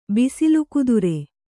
♪ bisilu kudure